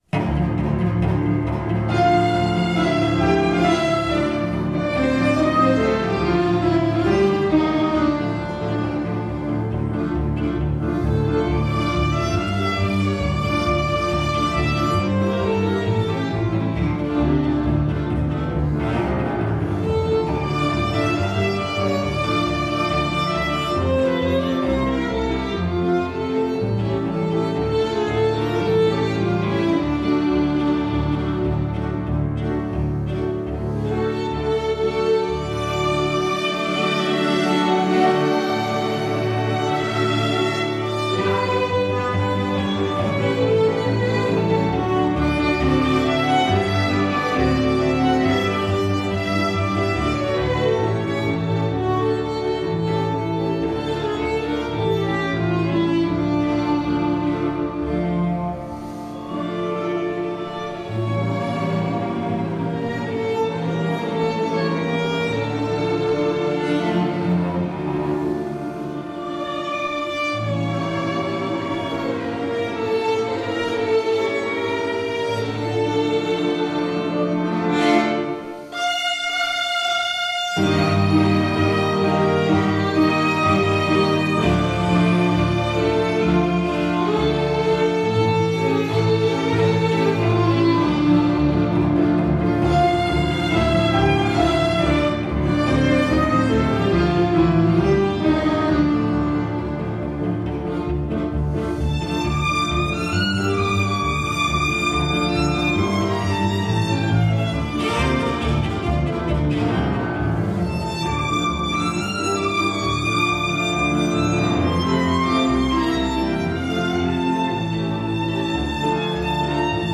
Marechiare (Canzone Napolitana), Florentiner Marsch, La calunnia (Rossini) Intermezzo (Mascagni), Duo voloncello/contrabasso (Rossini),